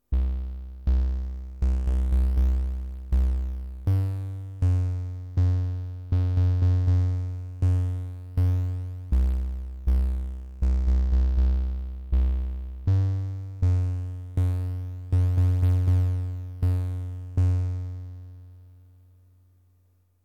Phaser
This provides additional interesting sound effects around this point and supports V/oct calibration via using the phaser as a VCO.
phaser.mp3